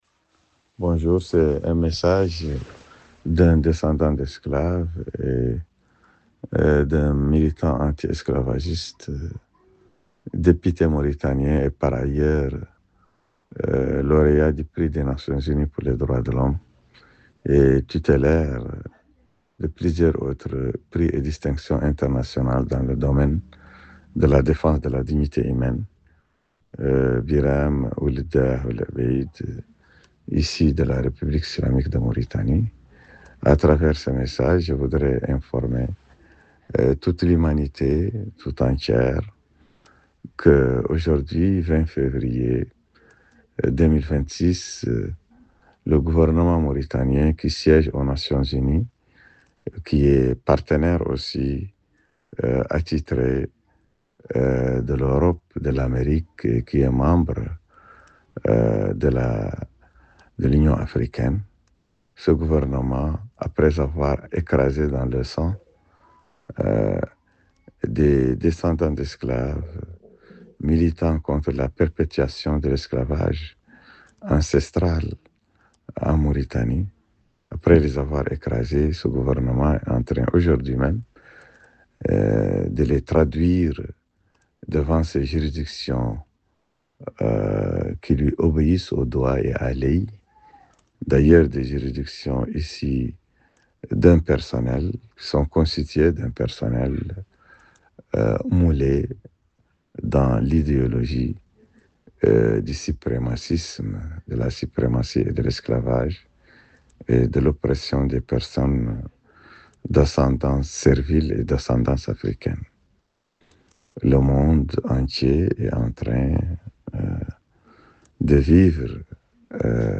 Message audio de Biram Dah Abeid : Répressions des militants anti esclavagistes en Mauritanie